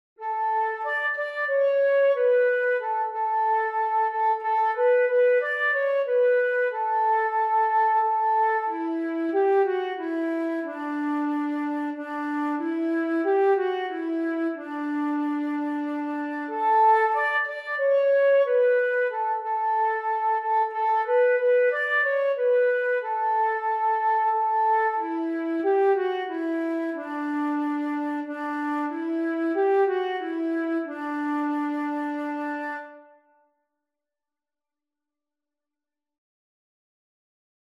In tieferer Tonart (in C)